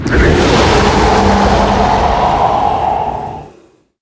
c_alien_bat3.wav